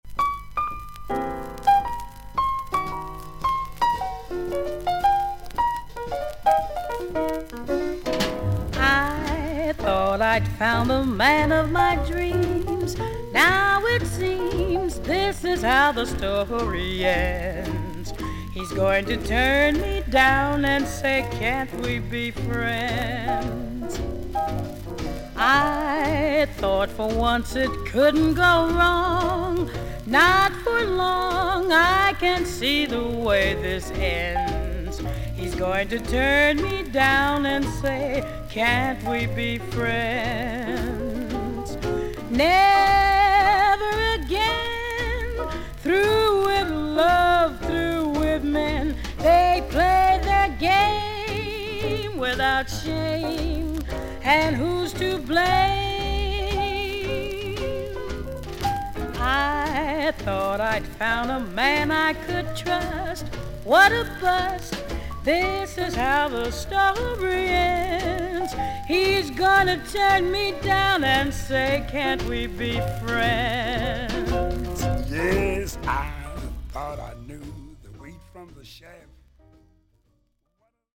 少々軽いパチノイズの箇所あり。少々サーフィス・ノイズあり。クリアな音です。
ジャズ名シンガー2人のデュエット・アルバム。